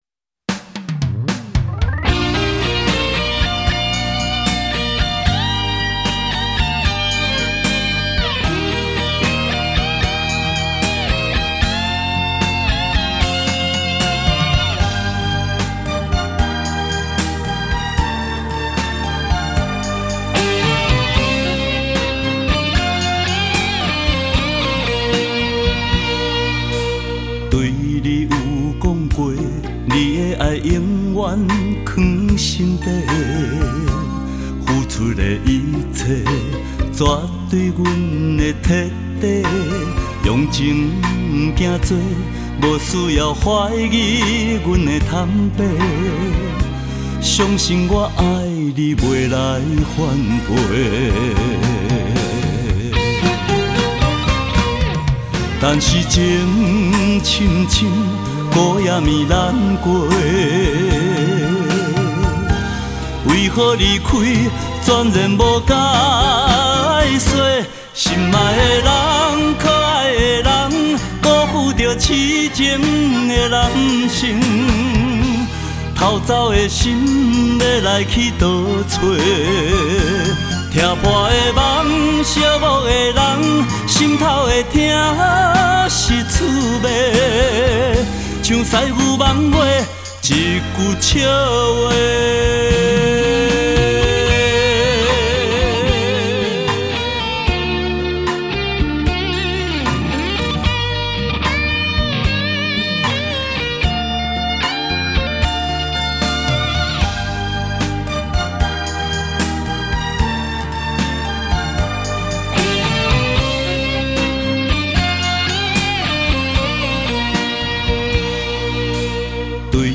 【台語】
优美的歌声加上丰富的肢体语言让他在台语歌坛上成为一位不可多得的好歌手!